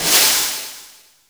SC B-SNARE 2.wav